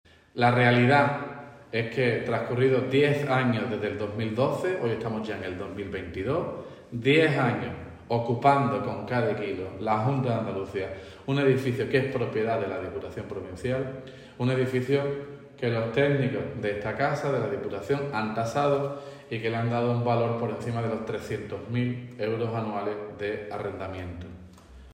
Vídeo: Declaraciones de Ruiz Boix sobre la reclamación a la Junta